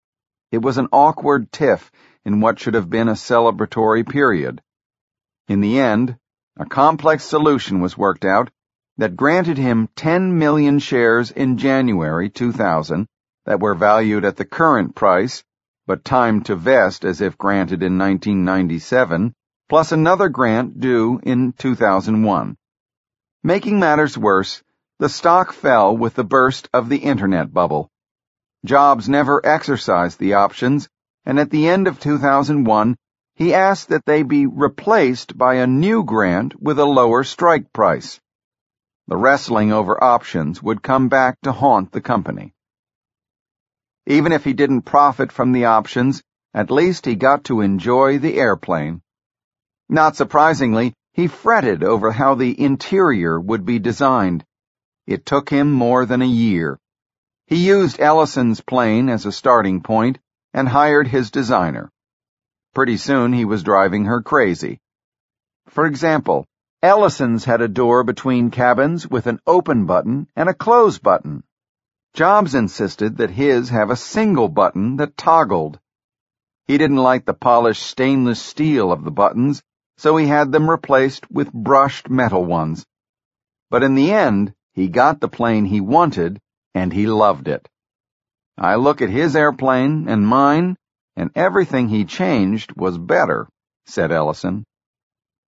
在线英语听力室乔布斯传 第474期:从iCEO到CEO(3)的听力文件下载,《乔布斯传》双语有声读物栏目，通过英语音频MP3和中英双语字幕，来帮助英语学习者提高英语听说能力。
本栏目纯正的英语发音，以及完整的传记内容，详细描述了乔布斯的一生，是学习英语的必备材料。